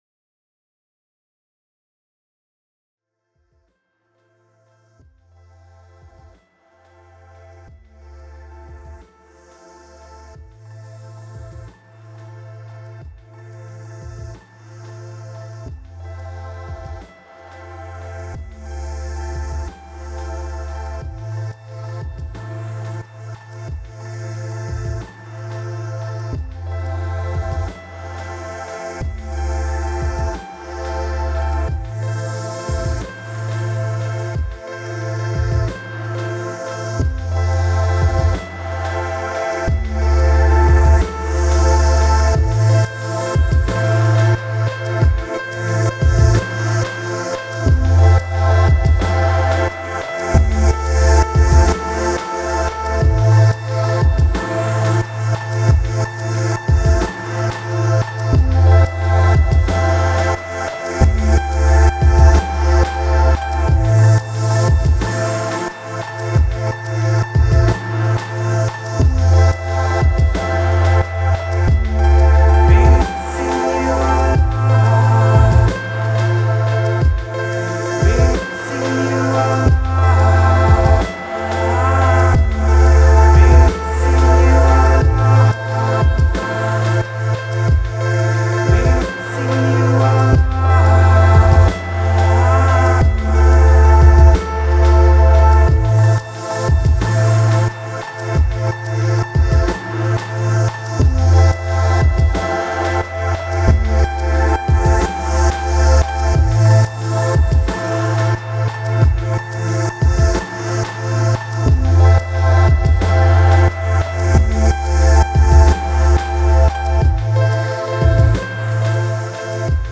Un titre plutôt court, globalement instrumental
enivrant et vertigineux.